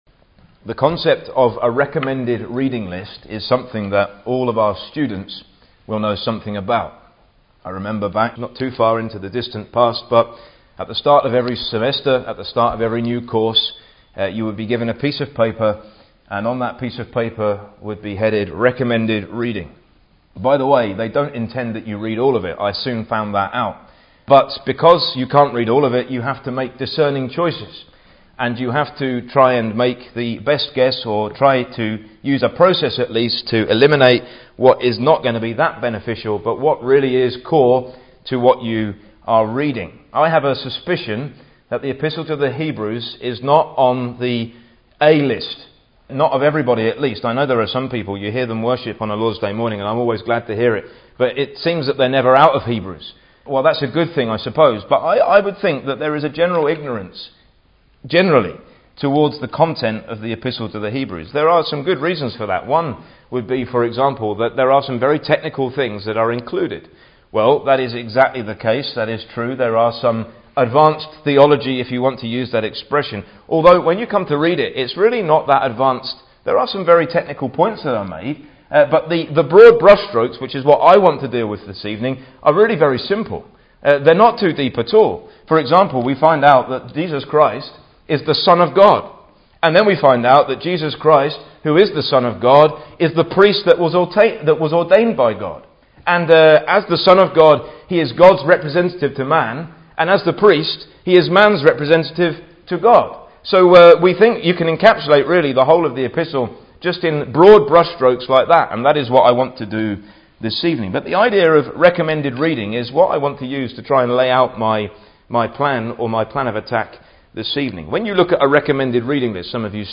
The theme of Christ’s supremacy and superiority and how the epistle’s author presents it (construction, progression and conclusion) is laid out chapter by chapter. Hebrews is a vital New Testament book – a working knowledge of its contents will greatly edify and benefit the Bible student (Message preached 17th Mar 2016)